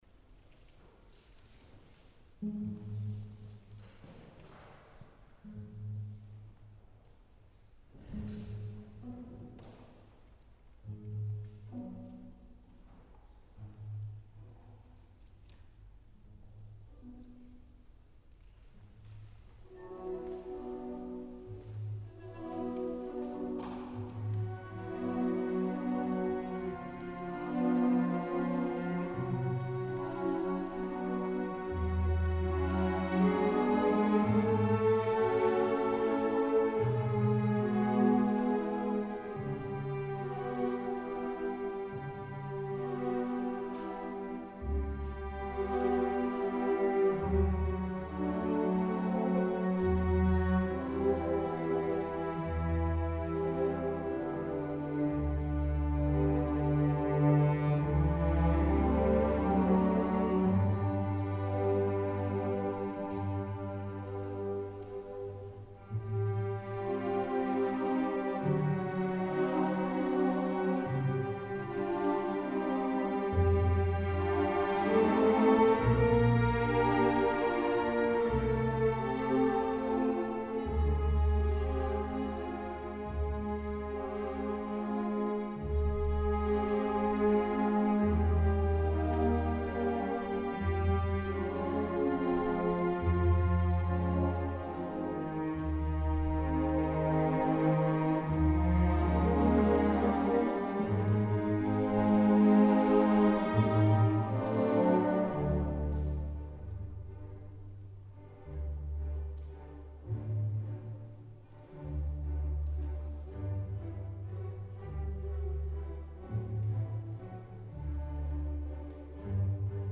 Es spielt das E.T.A-Hoffmann- Kammer-Orchester Berlin